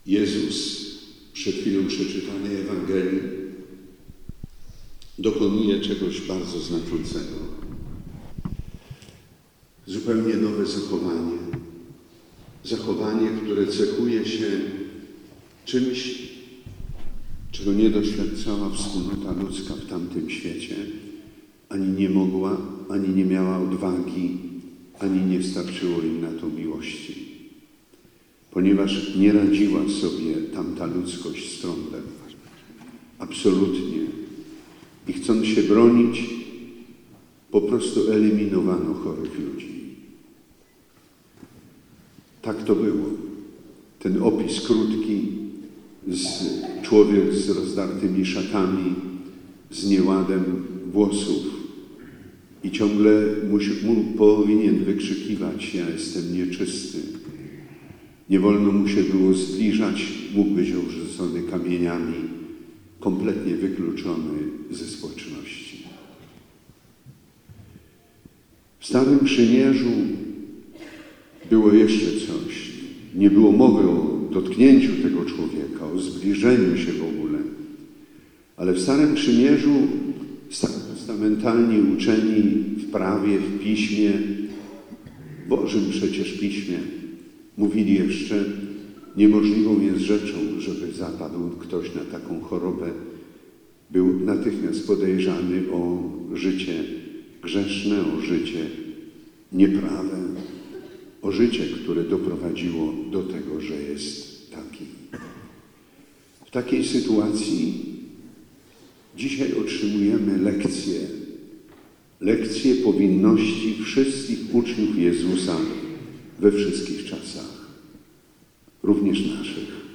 Szczecinek, 11 lutego: bp Edward Dajczak głosi homilię w kościele pw. św. Franciszka autor zdjęcia: ks.
Zapraszamy do wysłuchania całości homilii: /files/media/pliki/bpDajczak_hospSzcz.mp3